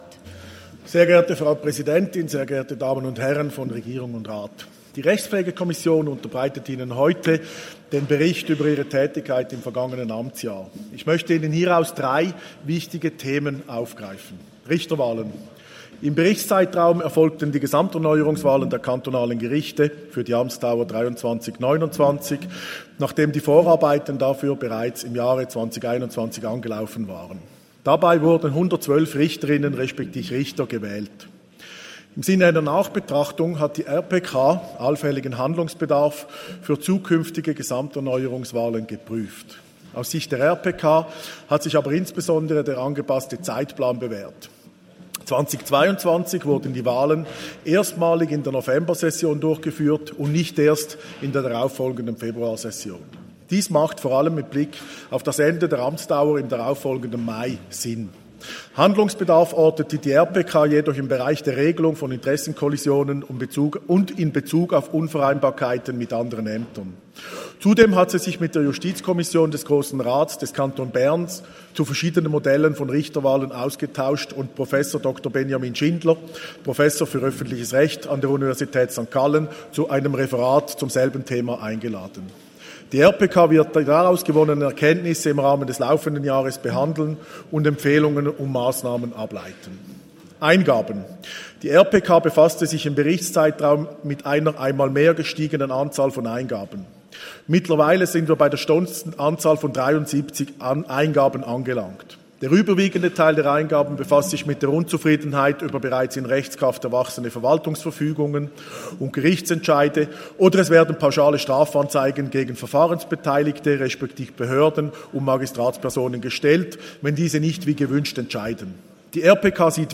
14.6.2023Wortmeldung
Session des Kantonsrates vom 12. bis 14. Juni 2023, Sommersession